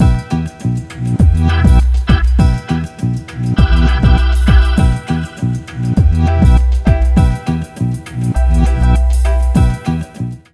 RAGGALOOP2-R.wav